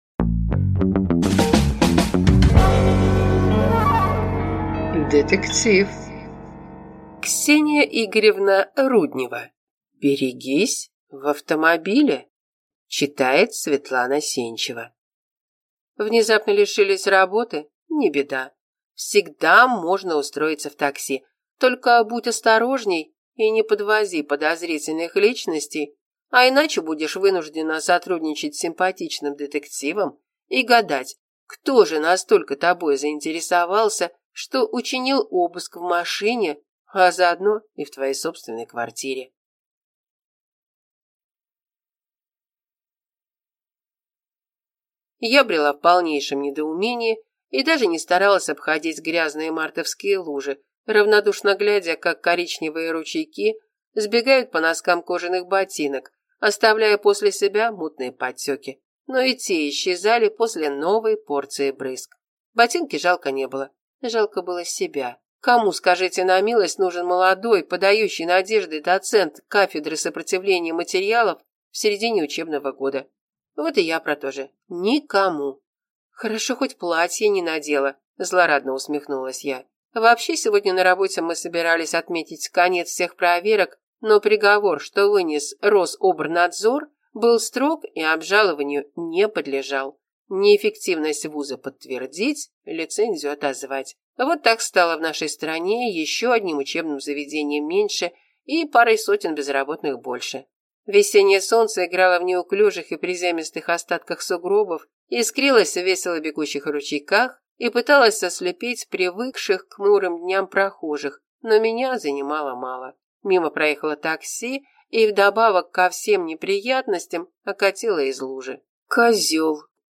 Аудиокнига Берегись в автомобиле | Библиотека аудиокниг